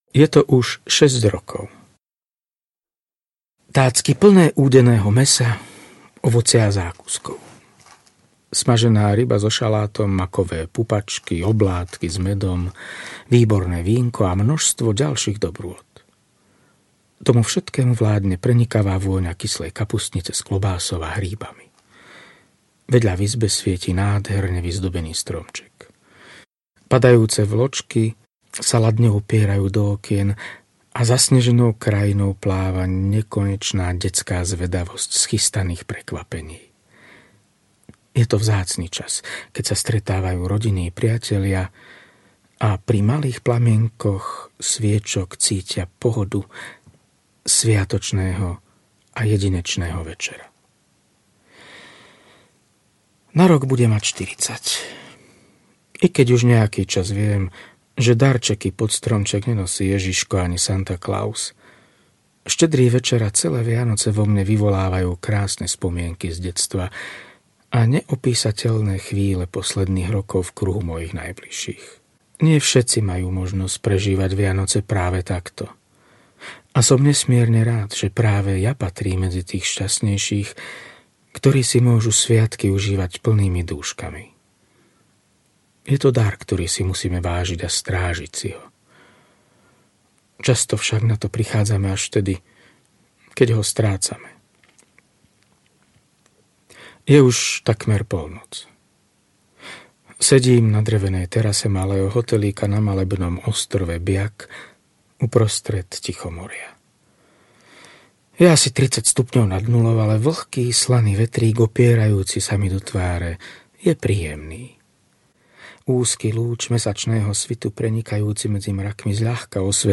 Územie duchov audiokniha
Ukázka z knihy